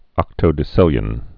(ŏktō-dĭ-sĭlyən)